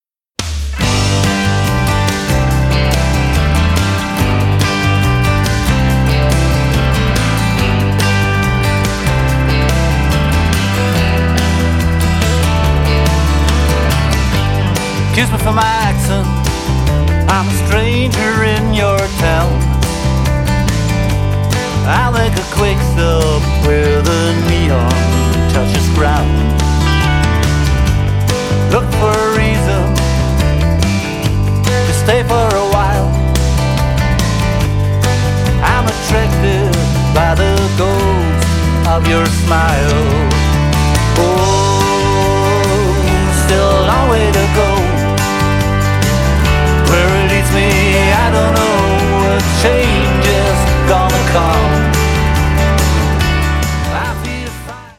My journey towards Americana continues…
Vocals and harp
Drums, keyboards, string arrangement
Electric and acoustic Guitars, Mandoline